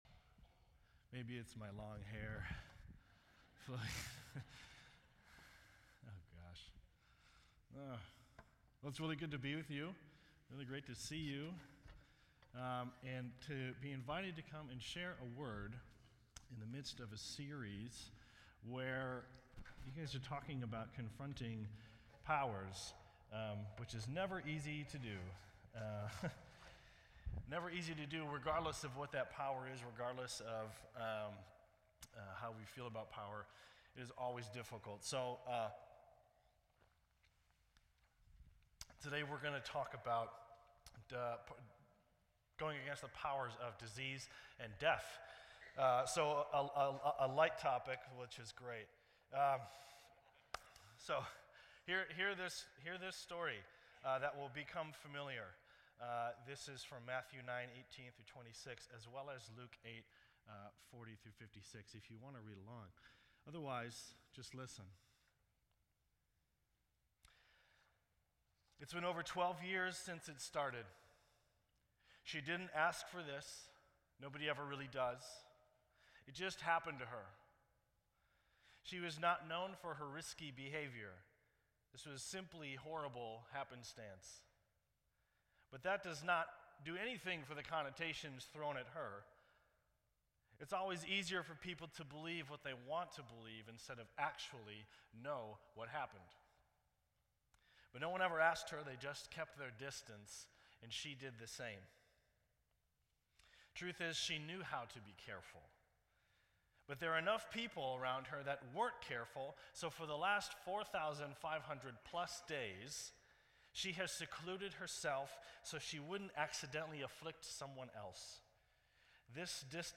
This entry was posted in Sermon Audio on March 27